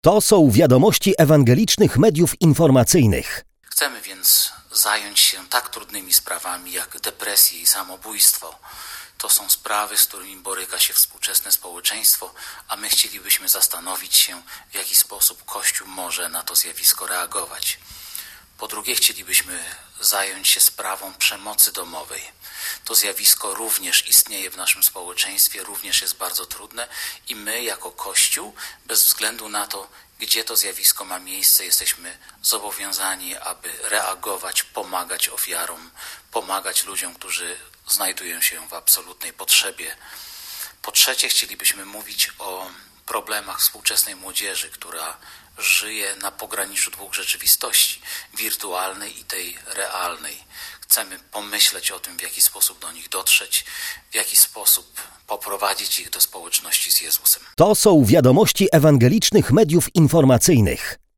Do wzięcia udziału w wydarzeniu zachęca biskup Kościoła Zielonoświątkowego w Polsce, Marek Kamiński.